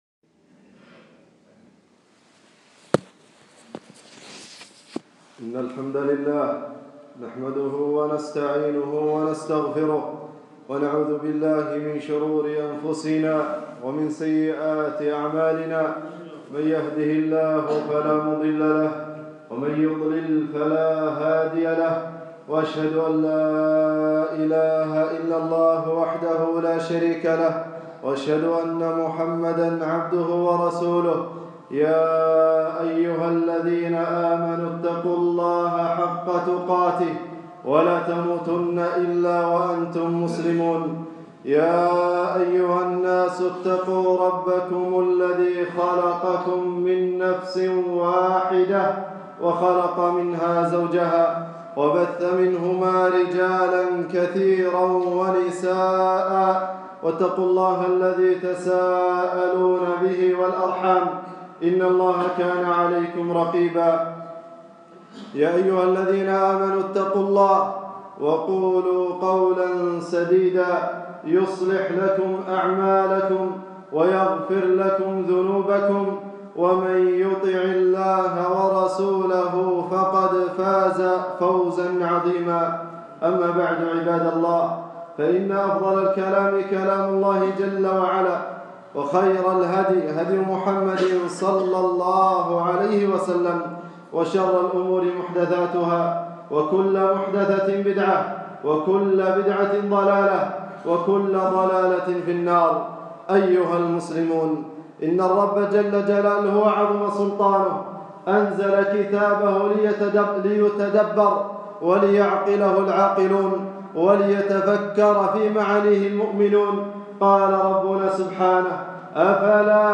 خطبة - تفسير سورة الفاتحة 5-5-1440 هــ